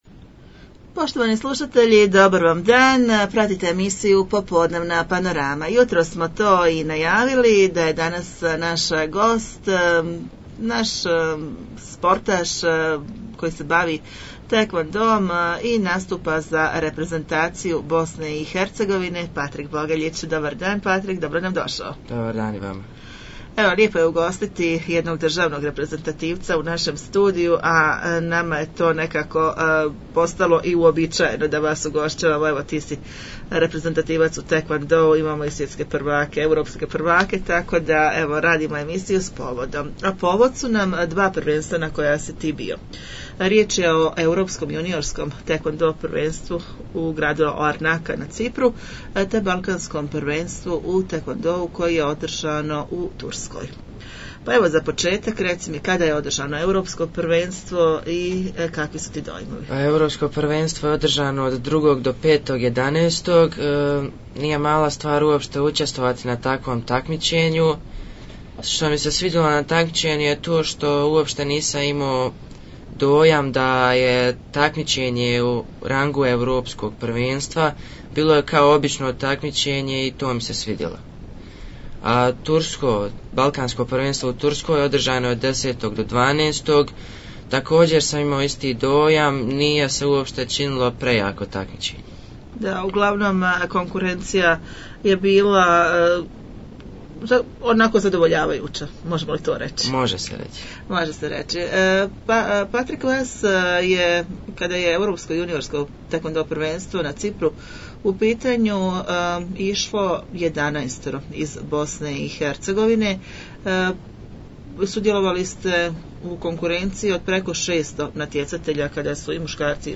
razgovor